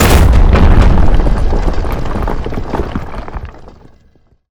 rock_earthquake_impact_01.wav